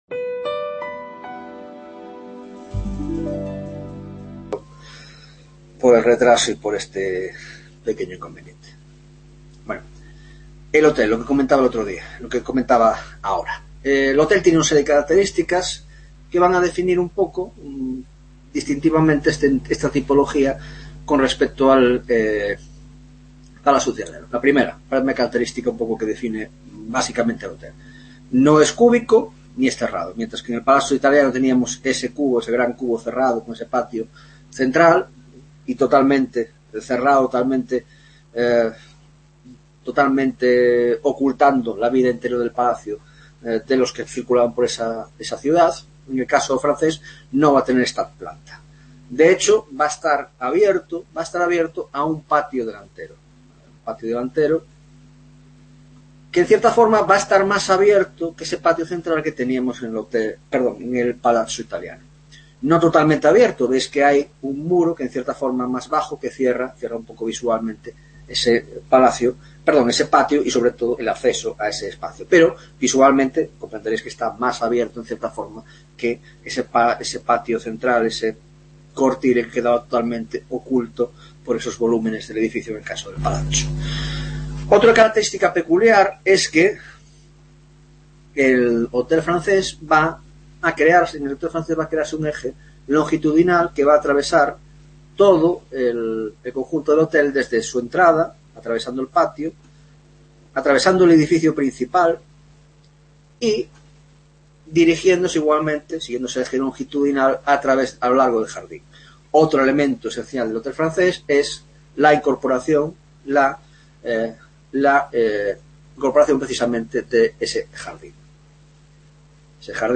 9ª Tutoría de Órdenes y Espacio en la Arquitectura Moderna - Tipologías Arquitectónicas: El Hotel frances; la Villa y la Villa Suburbana en Italia